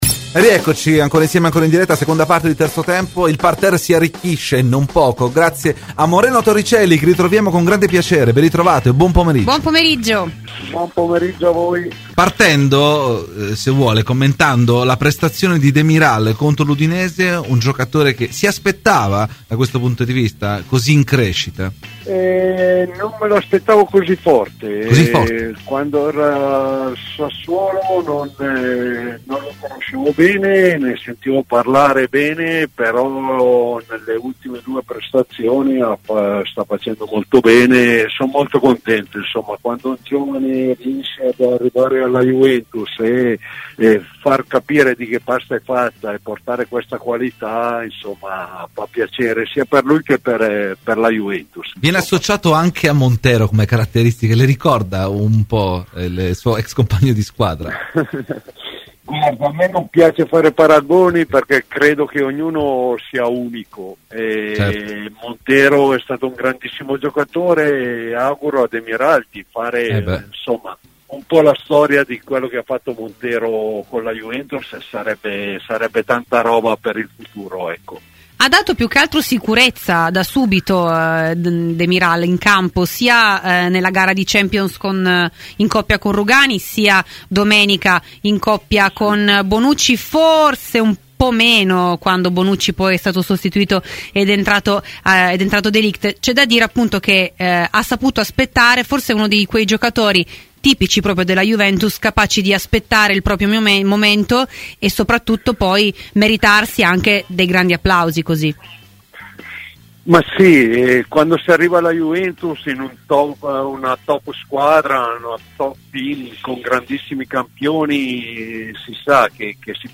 Ai microfoni di Radio Bianconera, nel corso di ‘Terzo Tempo’, è intervenuto l’ex Juventus Moreno Torricelli: “Demiral? Non me lo aspettavo così forte, quando era al Sassuolo non lo conoscevo bene, ma nelle ultime prestazioni sta facendo molto bene e sono contento.